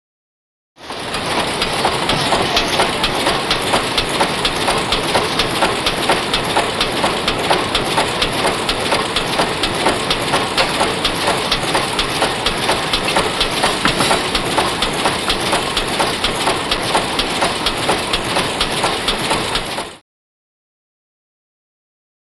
Sorting Machines
Cigarette Sorting Machine; Cigarette Sorting Machine. Constant Fast Clack Clack.